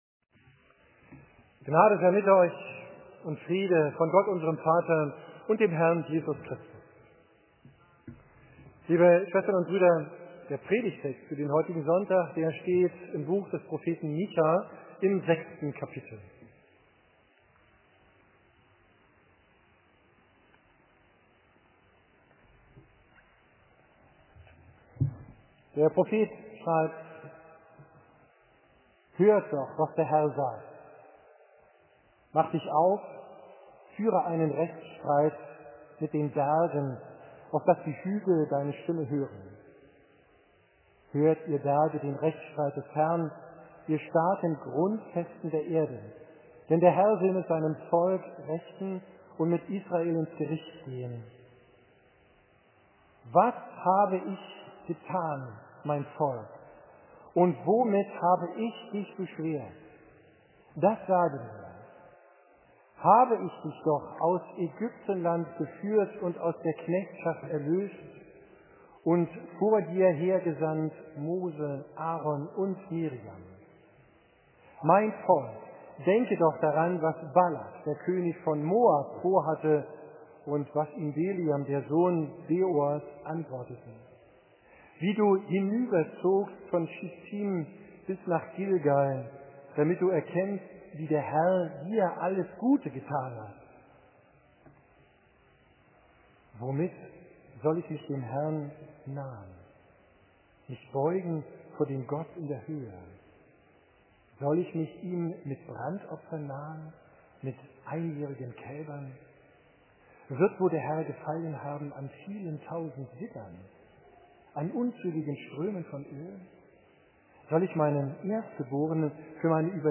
Predigt vom 22.